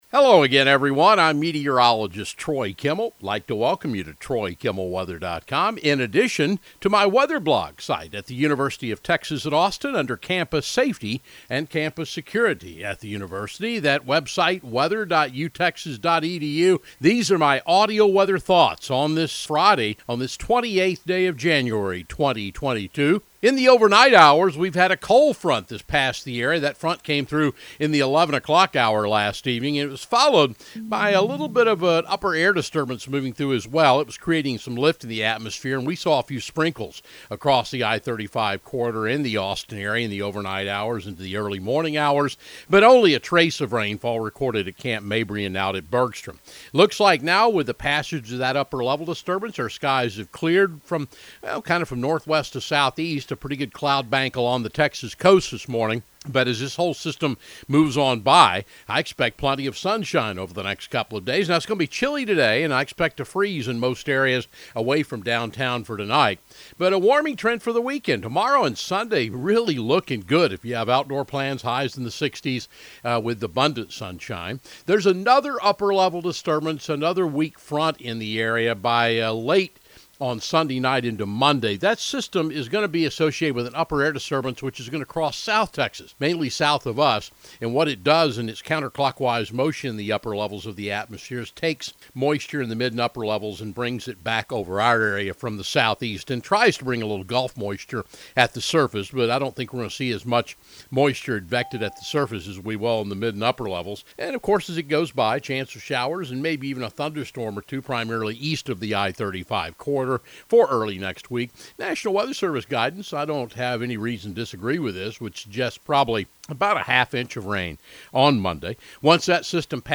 Audio Weather Webcast